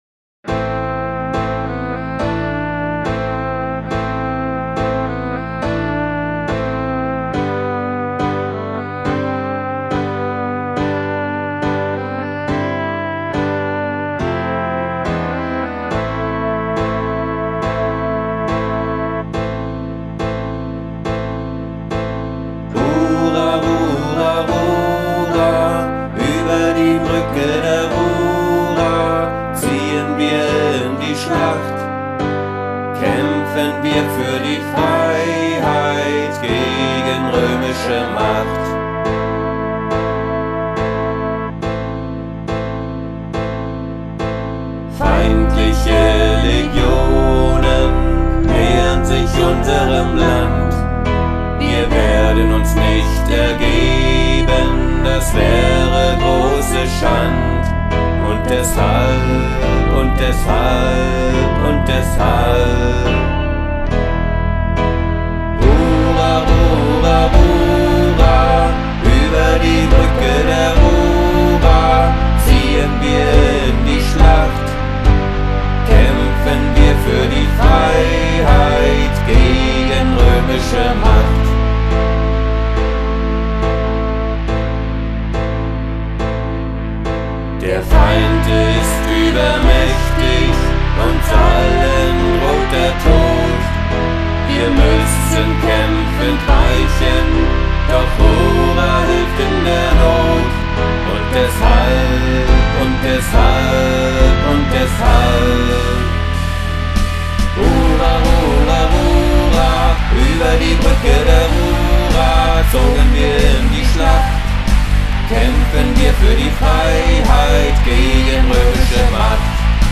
Instrumente
Gesang